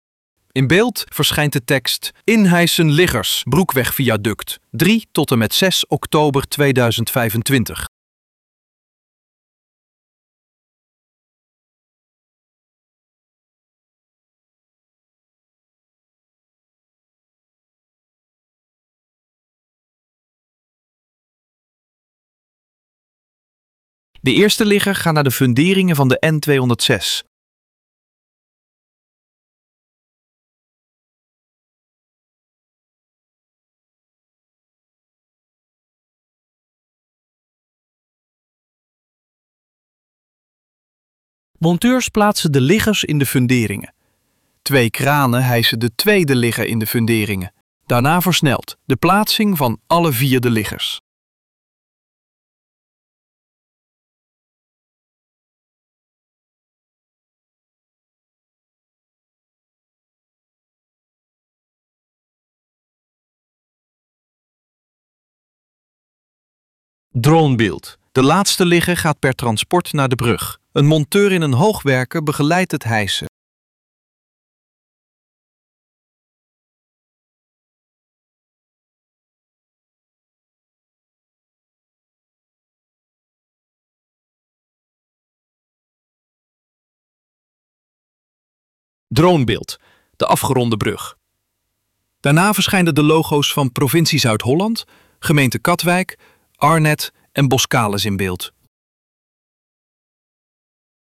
audiodescriptie_busbaan_katwijk_leiden.mp3